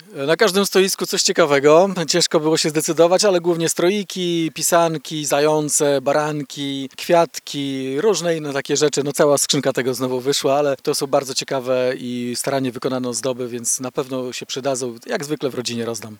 – Warto wspierać takie inicjatywy – mówi Iwaszkiewicz.